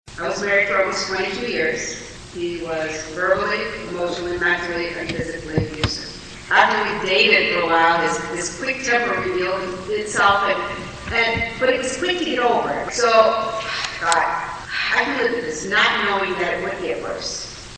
Domestic And Sexual Abuse Services held their Candlelight Vigil in the Auxiliary Gym at Sturgis High School.